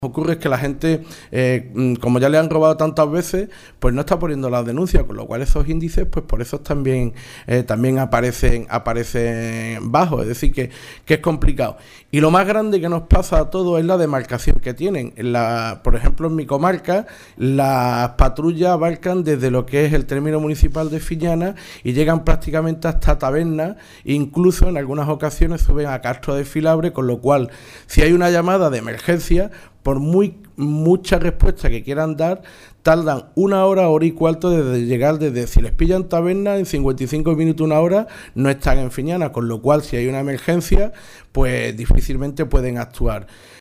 Rueda de prensa que ha ofrecido el Grupo Socialista en la Diputación Provincial, junto a alcaldes y portavoces del Andarax y la comarca de Nacimiento